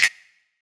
CXSNAPS.WAV